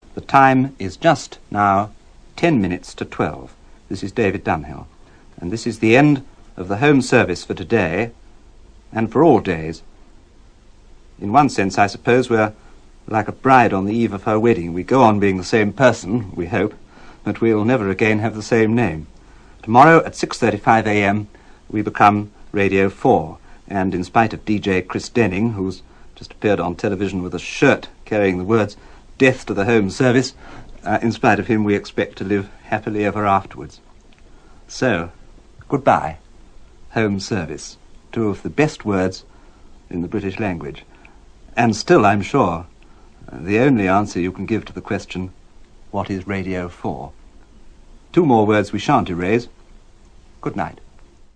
Here’s a clip of the Home programme on D-Day, June 6th, 1944.